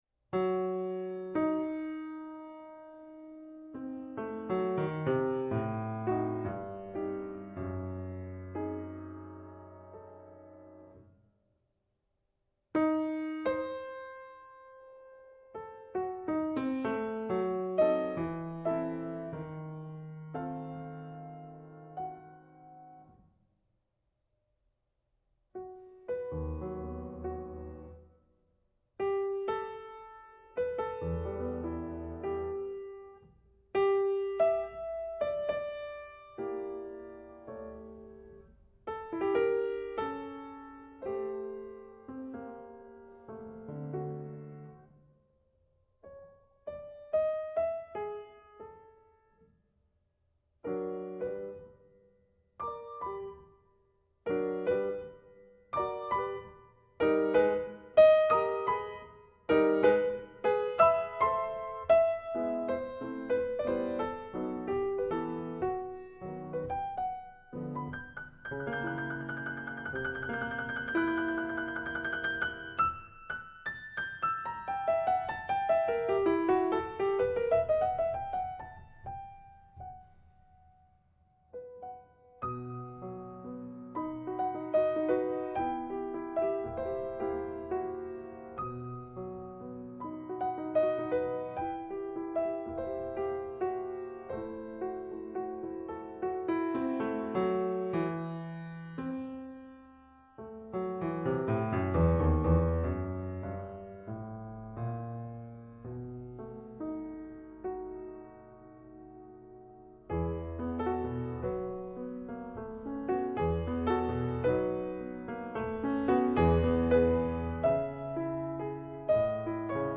Die Loreley Harmonika